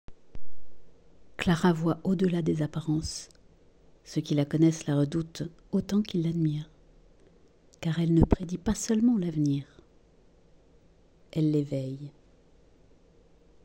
Voix off
40 - 60 ans - Mezzo-soprano